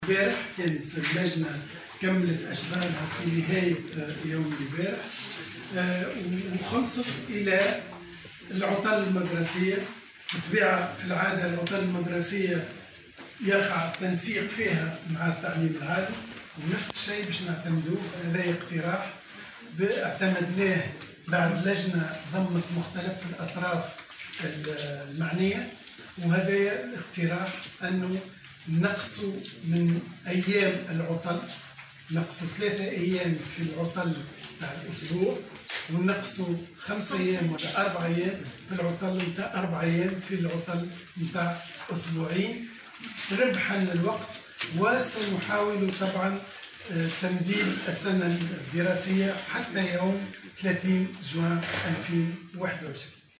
أعلن وزير التربية، فتحي السلاوي خلال ندوة صحفية عقدها اليوم عن جملة من الإجراءات الجديدة التي تهم السنة الدراسية الجديدة.